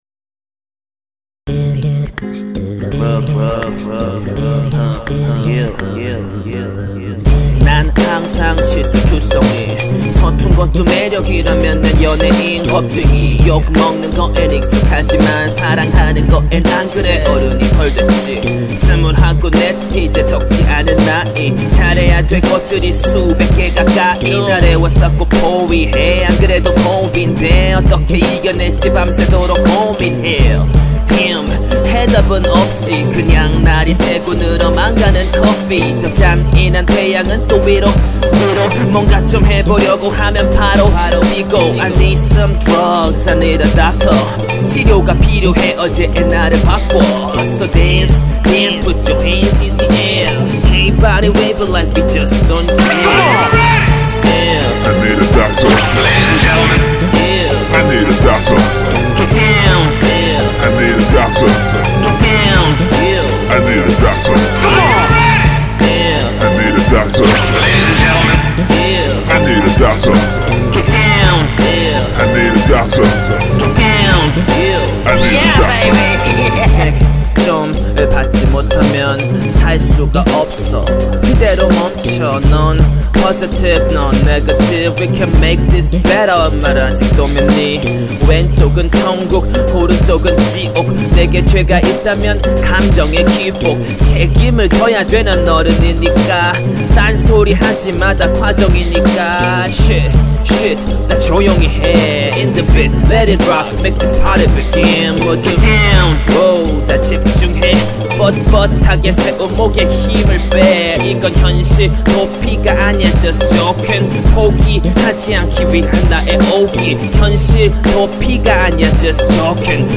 마약쟁이처럼비트를 찍어보고싶엇습니다ㅋㅋ
하이퍼소닉이랑 pro53이랑 트릴로지랑 ewql오케스트라썻습니다 ㅋㅋ